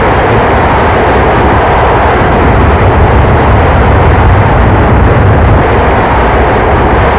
cfm-reverse.WAV